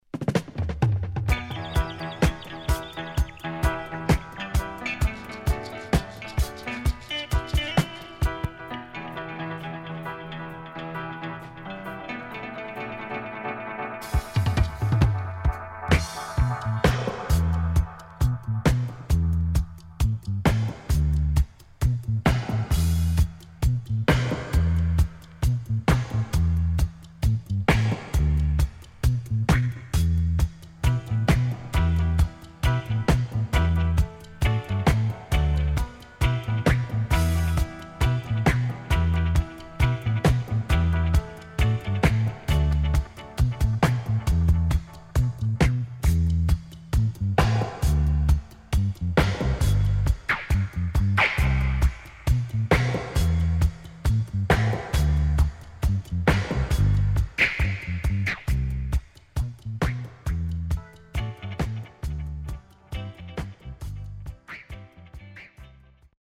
HOME > REISSUE [REGGAE / ROOTS]